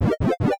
levelUp.wav